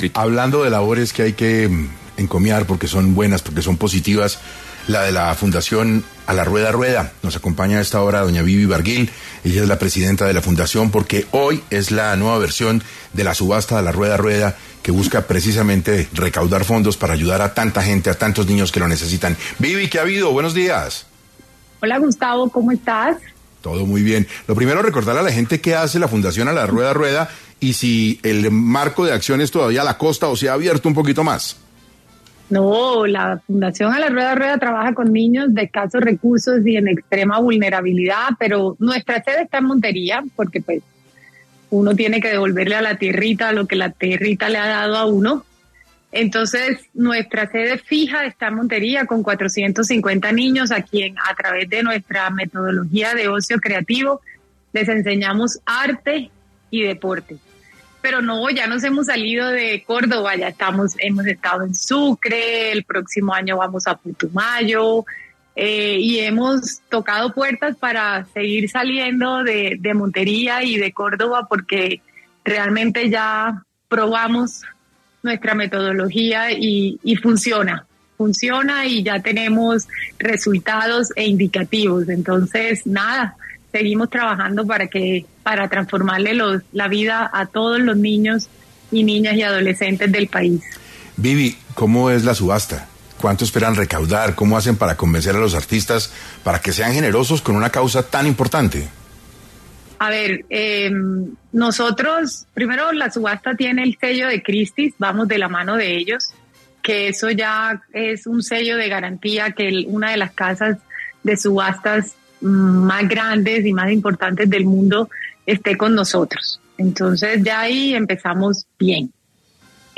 En entrevista con la mesa de trabajo del programa 6AM